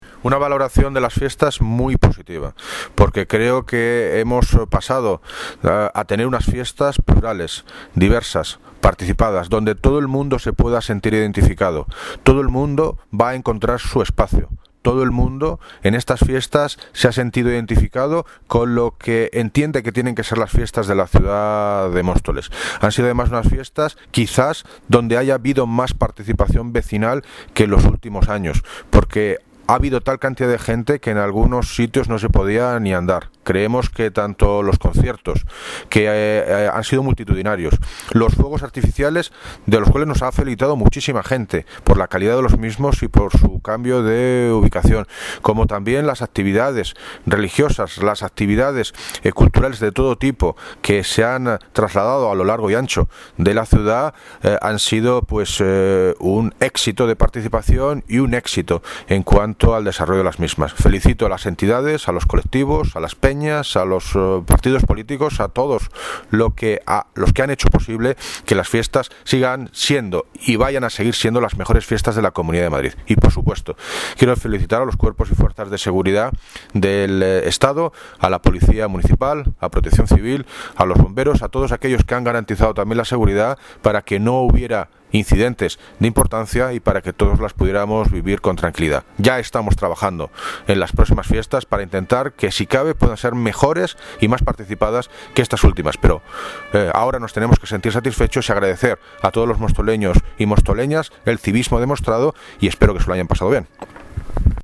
Audio - David Lucas (Alcalde de Móstoles ) Sobre Valoración de las Fiestas